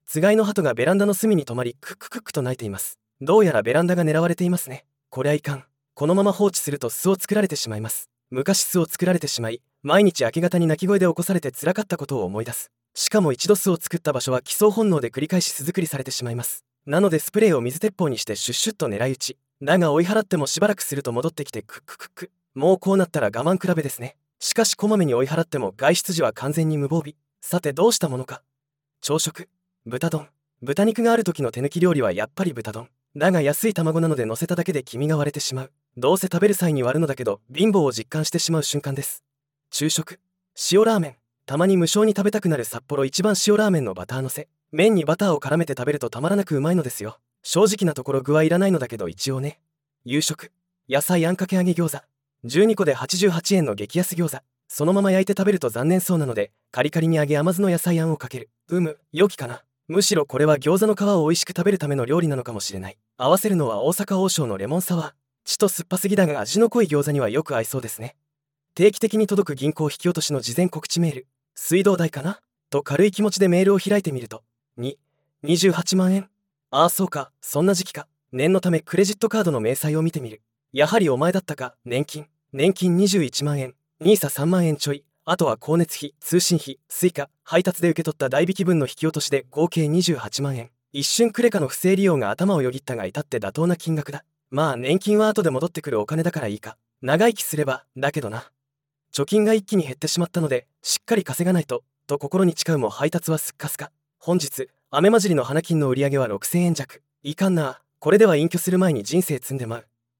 つがいのハトがベランダの隅にとまりクッククックと鳴いています。
だが追い払ってもしばらくすると戻ってきてクッククック。